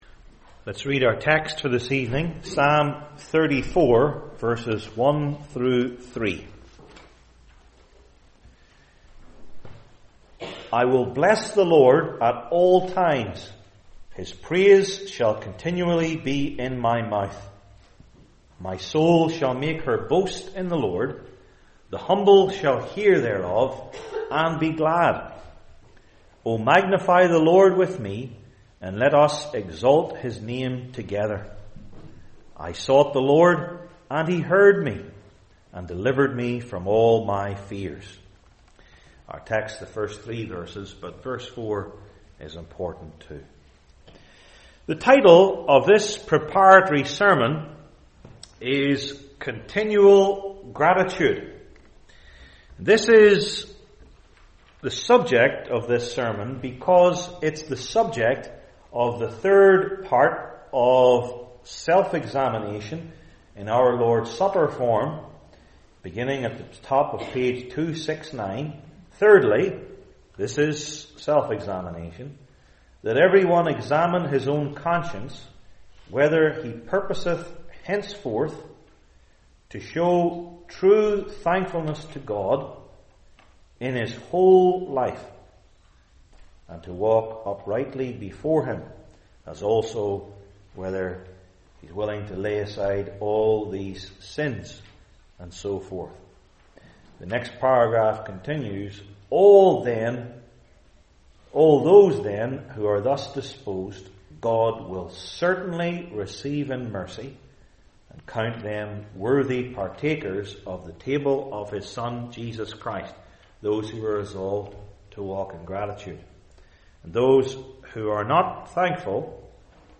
Old Testament Individual Sermons I. Its Expressions II.